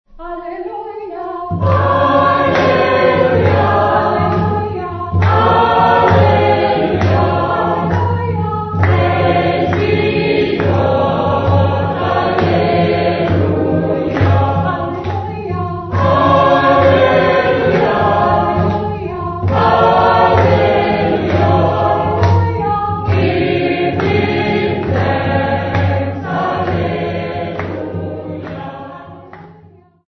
Khanyisa Church Choir
Folk music Africa
Sacred music South Africa
Church music South Africa
Field recordings South Africa
Missa Zimbabwe hymn, sung in English at music workshop, accompanied by the drum